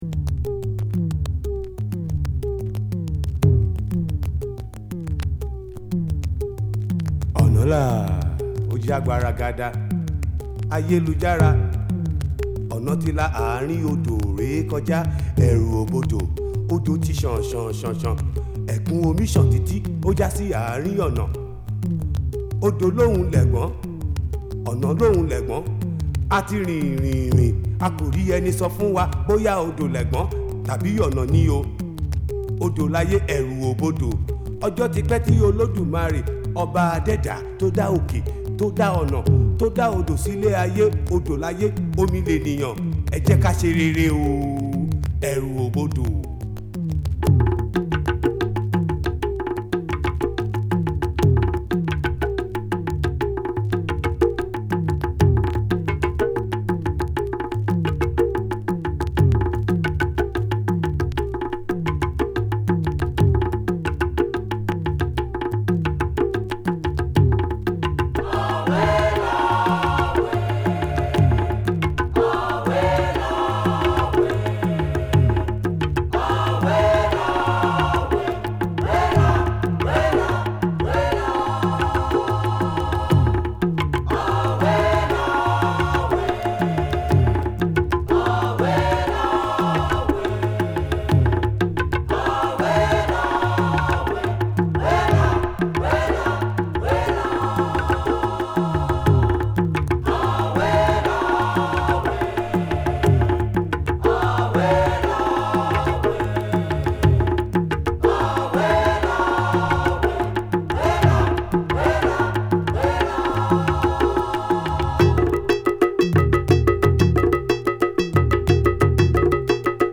独特の響きを持ったシンセドラムにキレの良いパーカッション、アフリカの大地を思わすコーラスが絡む